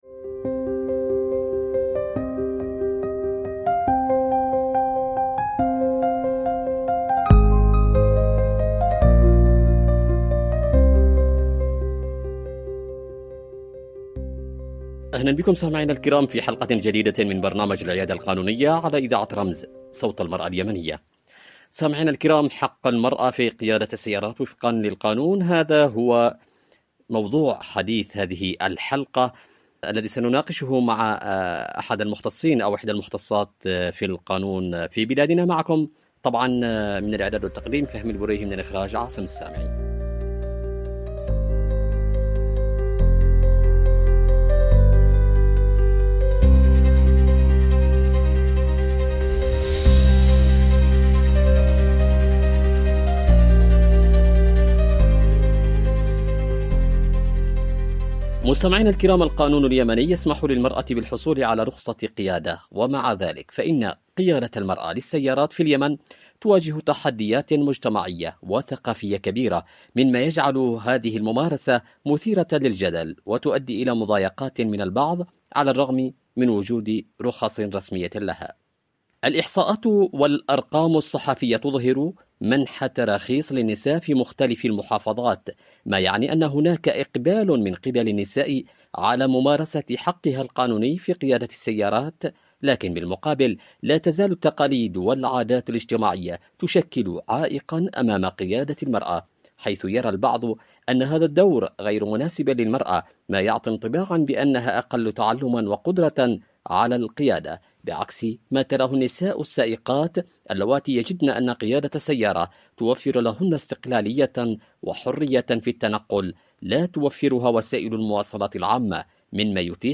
عبر إذاعة رمز